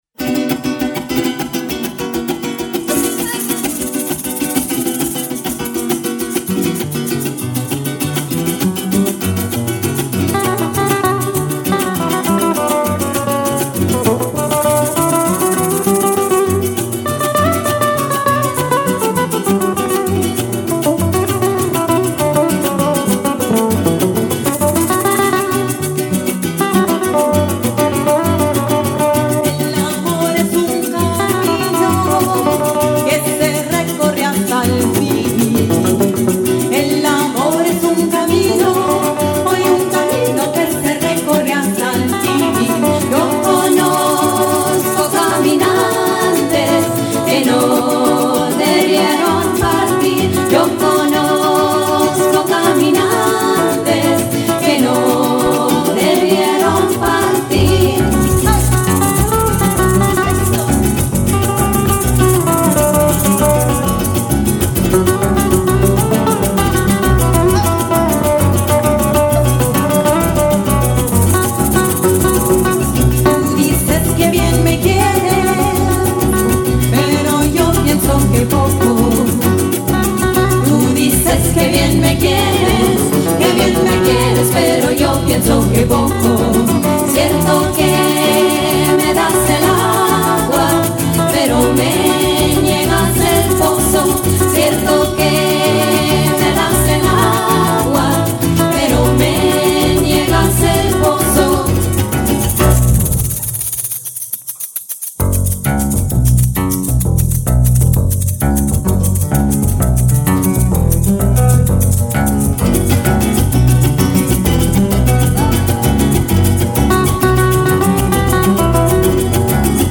Genre:Folk, World, & Country